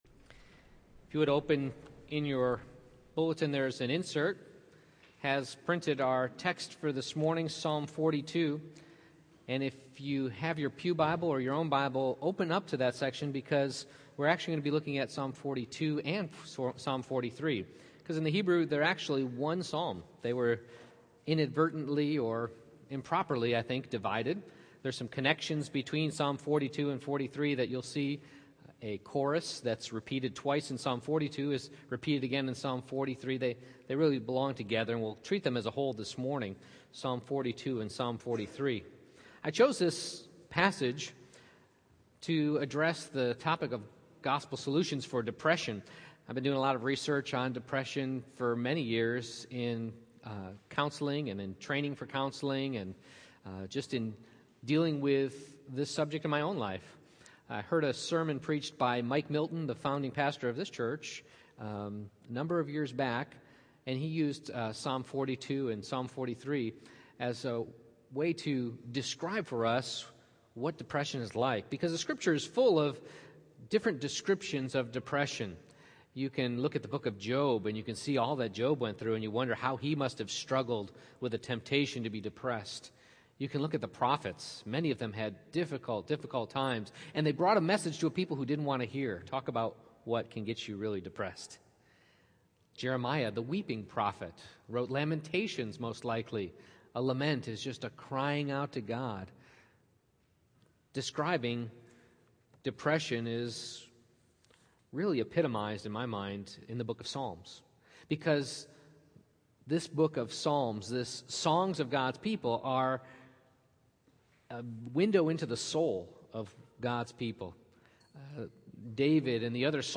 Psalm 42:1-43:5 Service Type: Morning Worship I. The Occasion